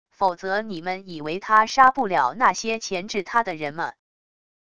否则你们以为他杀不了那些钳制他的人么wav音频生成系统WAV Audio Player